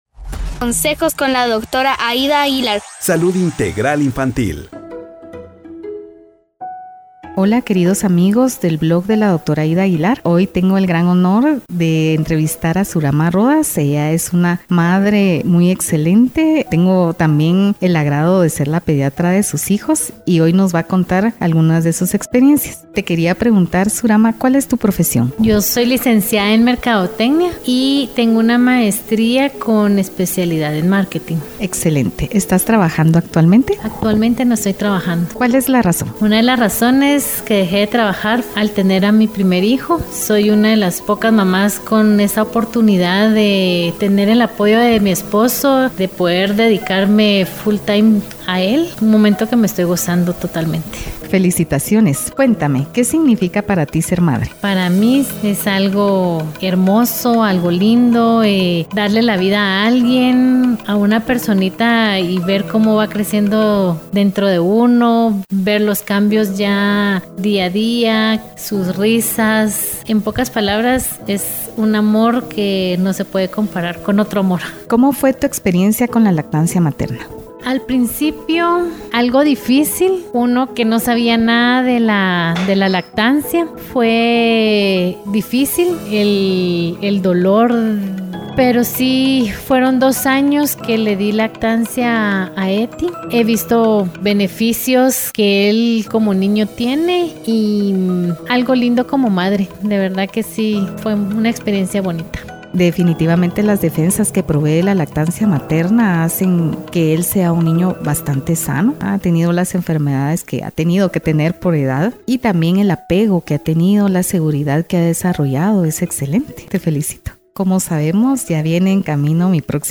Celebramos a las ¡Súper Mamás! Entrevista 1 Podcast #018